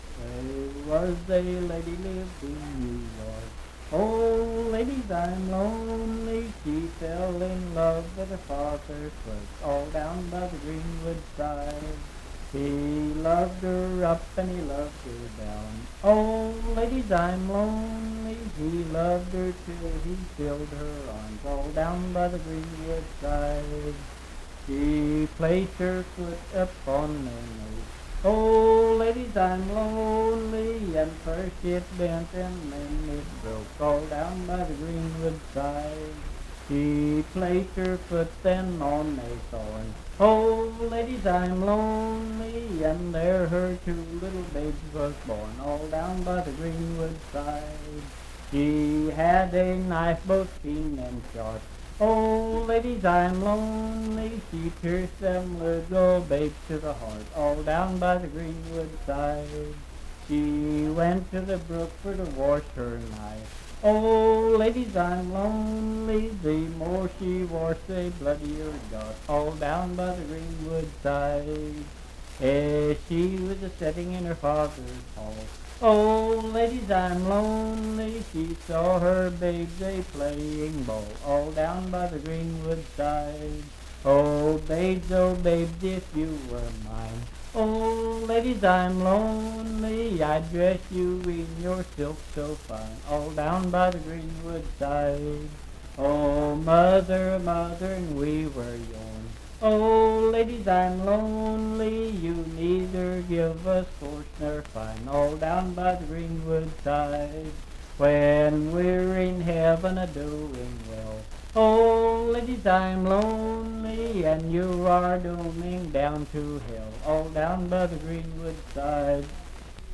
Unaccompanied vocal music
in Riverton, W.V.
Verse-refrain 10(4w/R).
Voice (sung)